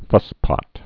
(fŭspŏt)